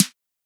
edm-snare-07.wav